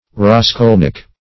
Raskolnik \Ras*kol"nik\ (r[a^]s*k[o^]l"n[i^]k), n.; pl.